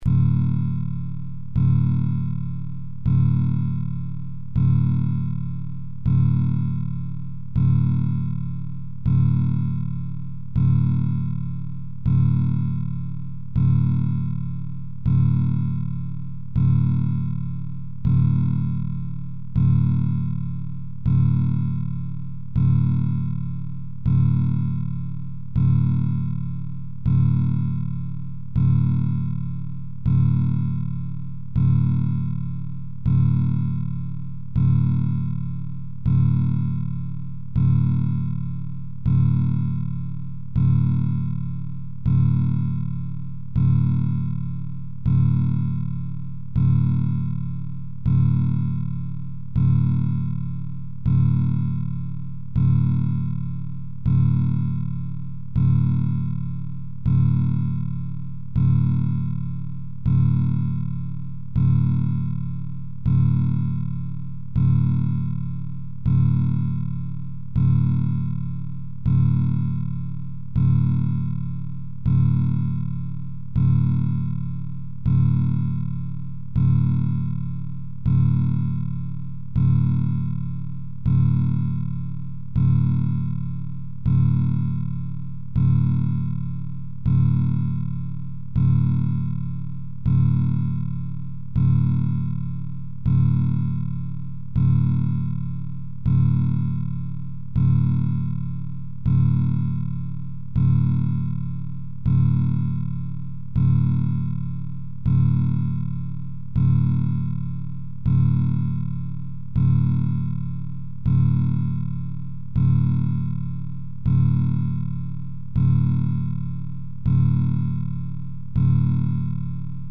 An analytic approach to prana yoga is supported by introduction of a steady metronomic beat, which is provided by an .mp3 recording available here
– 6.3 MB) The rate is 40 beats per minute; the length is 7.5 minutes.
bpm40.mp3